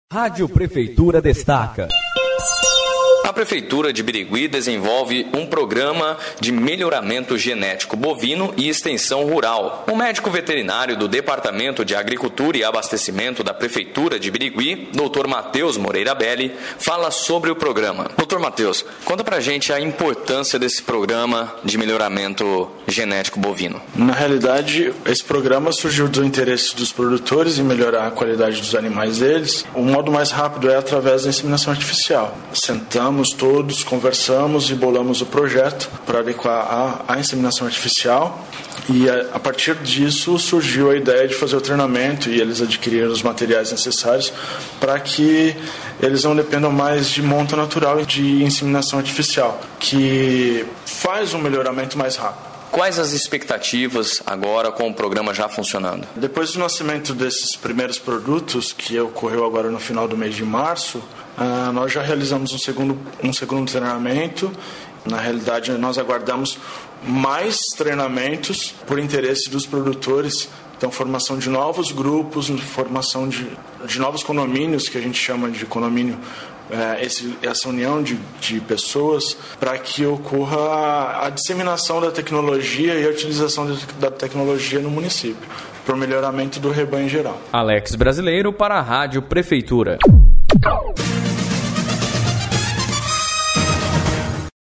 Sonora: